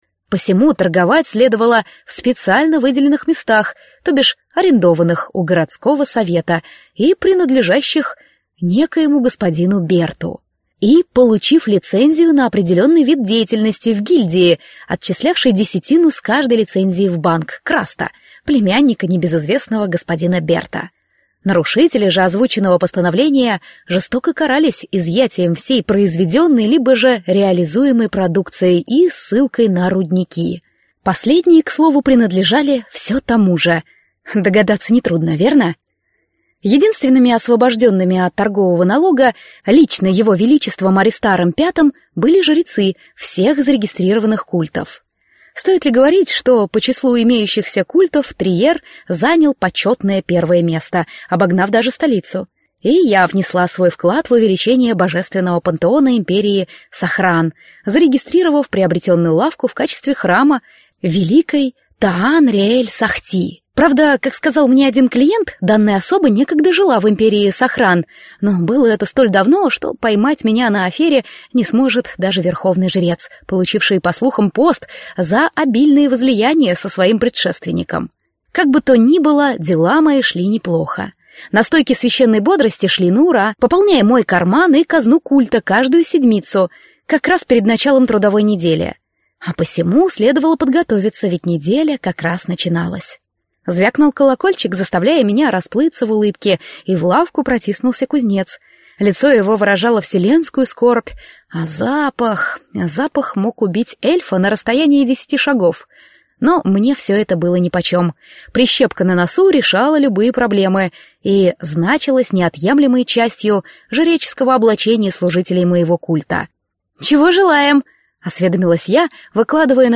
Аудиокнига Три глотка волшебного напитка | Библиотека аудиокниг